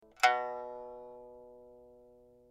pipa2.mp3